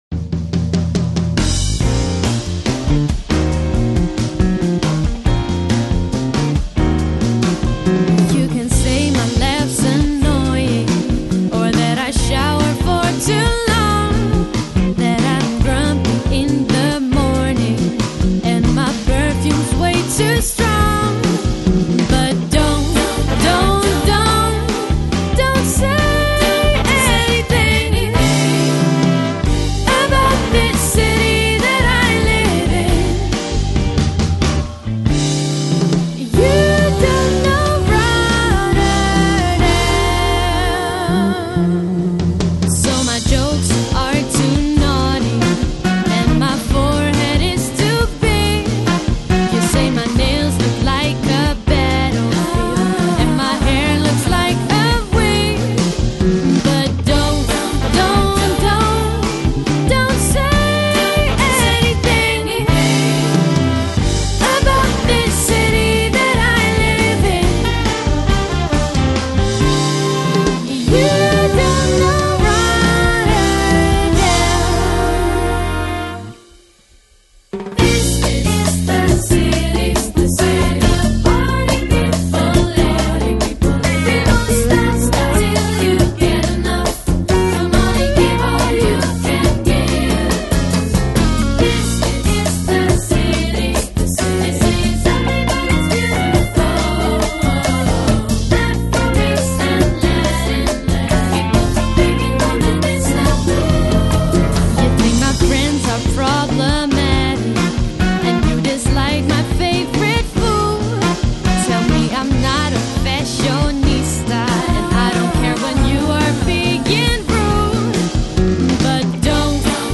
音乐风格: Jazz
风格类型：jazz/soul/pop